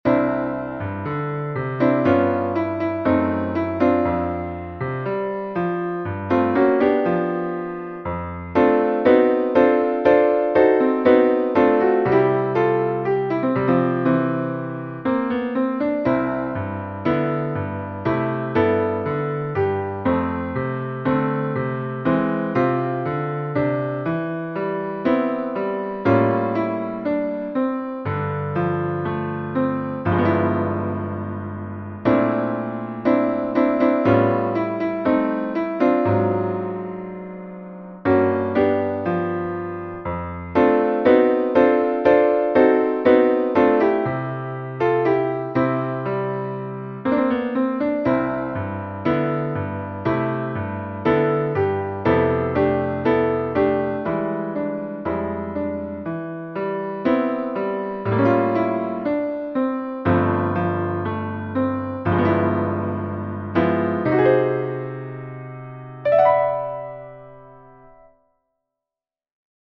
Rendu audio numérique